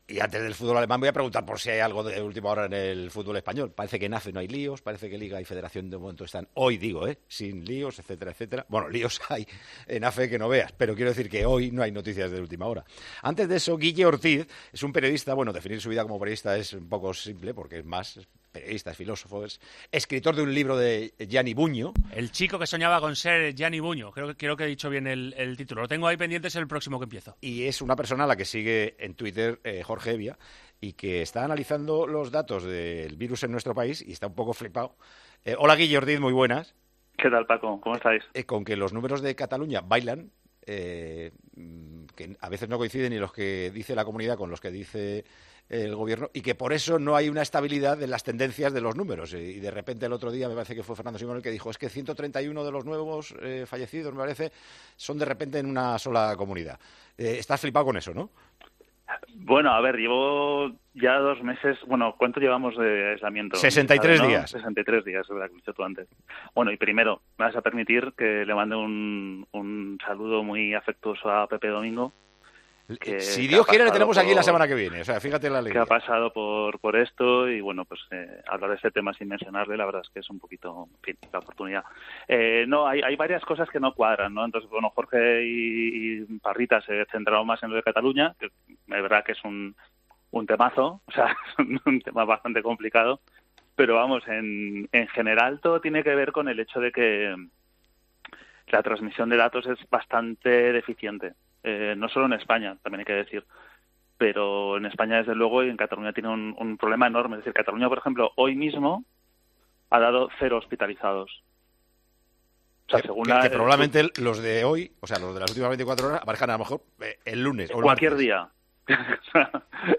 Hablamos con el escritor y filósofo.
Con Paco González, Manolo Lama y Juanma Castaño